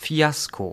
Ääntäminen
IPA: [ˈfi̯.as.ko] Tuntematon aksentti: IPA: /ˈfi̯.as.kos/